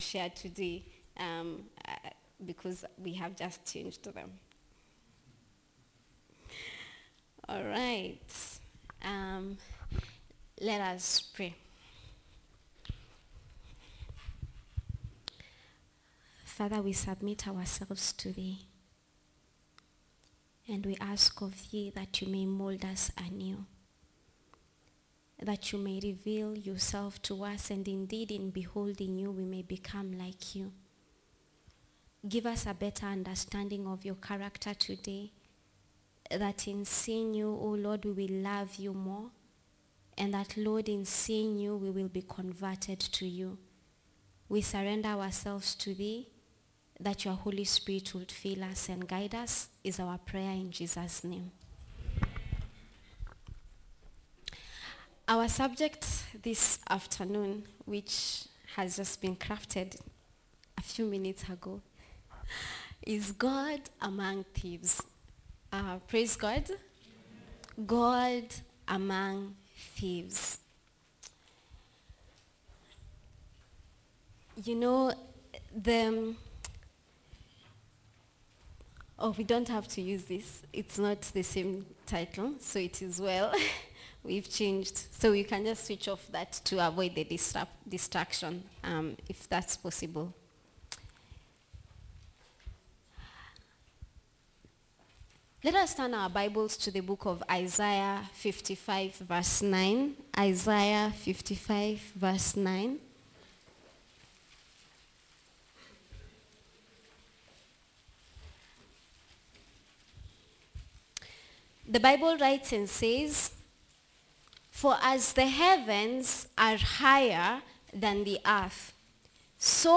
29720-Sermon.mp3